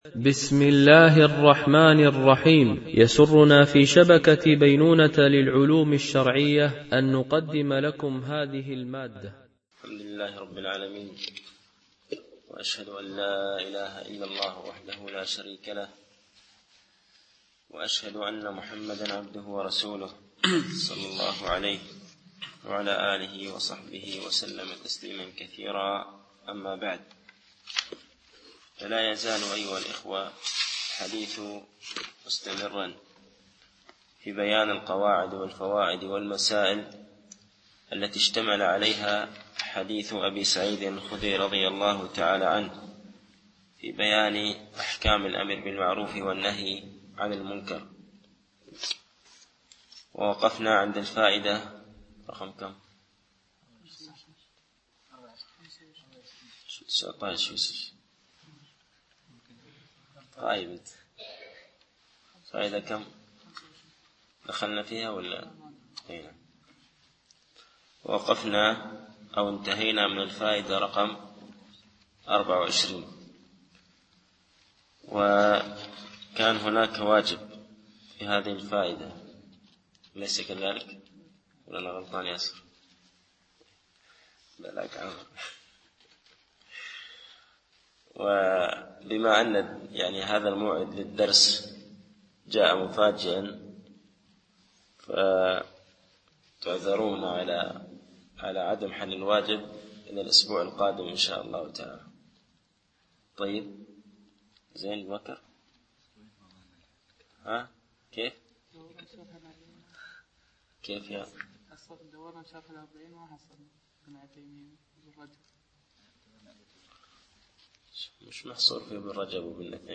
شرح أعلام السنة المنشورة ـ الدرس 164( تكملة سؤال - على من يجب الأمر بالمعروف والنهي عن المنكر وما مراتبه ؟)